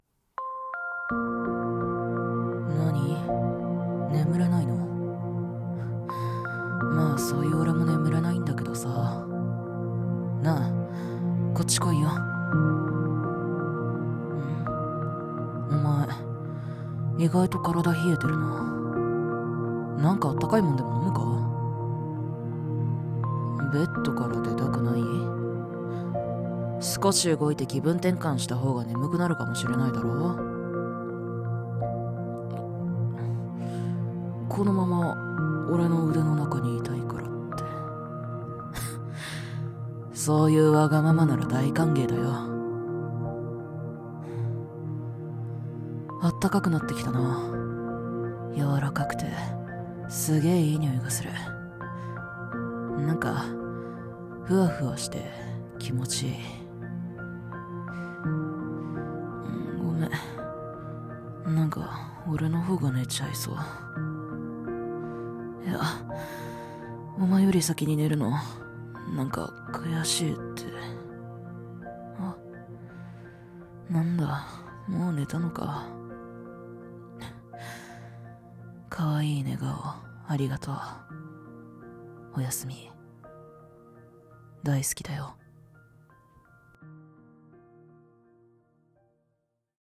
シチュエーションCD風